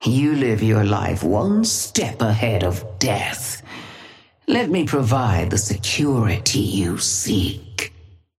Sapphire Flame voice line - You live your life one step ahead of death.
Patron_female_ally_wraith_start_09.mp3